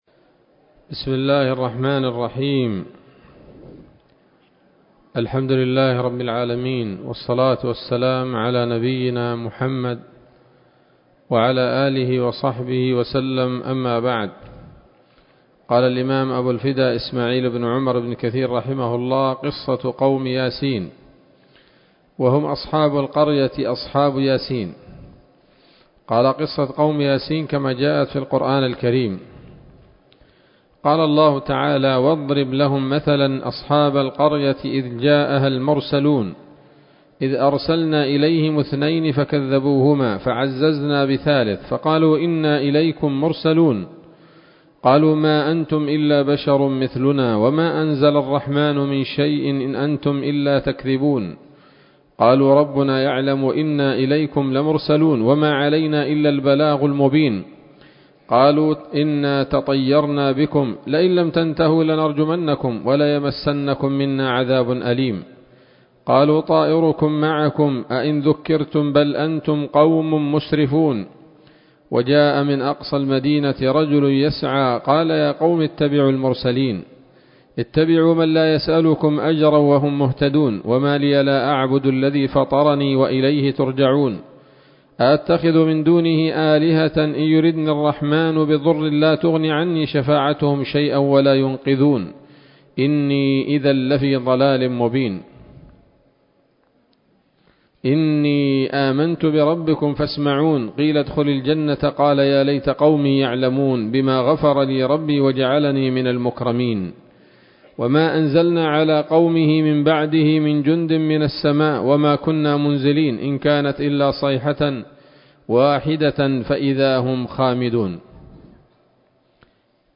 الدرس الثامن والسبعون من قصص الأنبياء لابن كثير رحمه الله تعالى